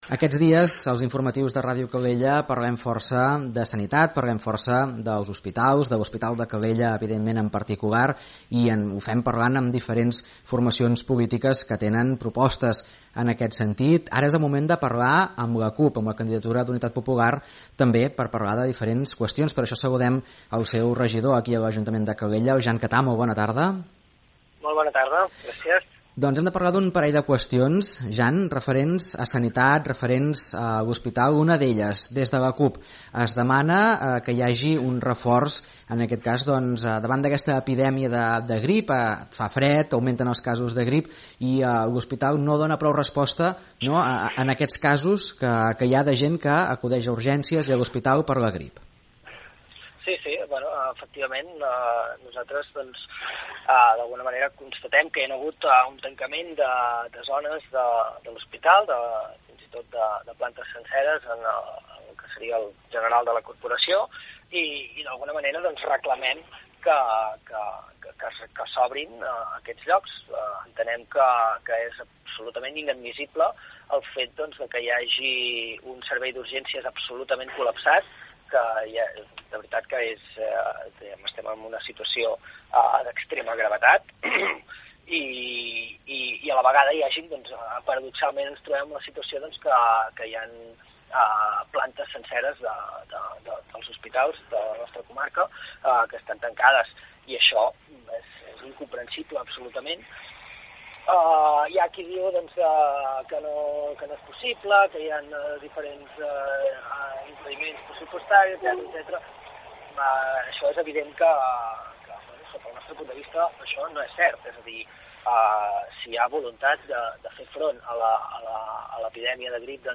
01_10-ENTREVISTA-SENCERA-JAN-CATA.mp3